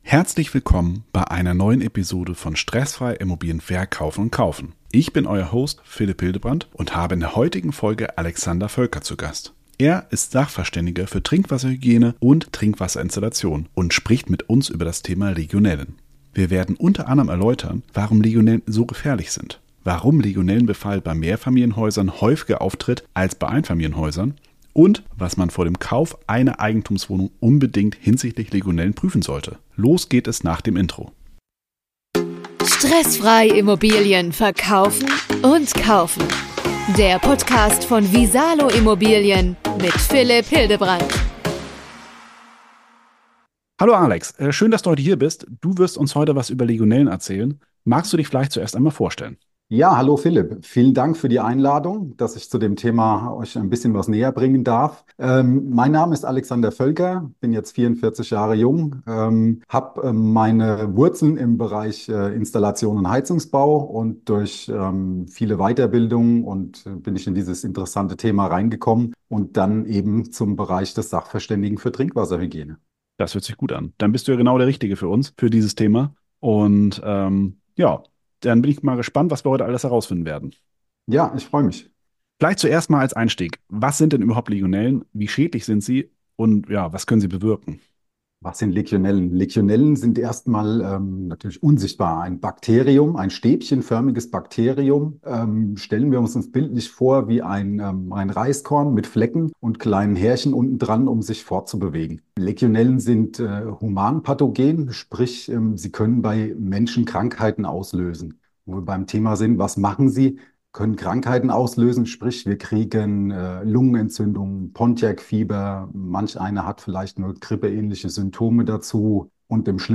Er ist Sachverständiger für Trinkwasserhygiene & Trinkwasserinstallationen und spricht mit uns über das Thema Legionellen.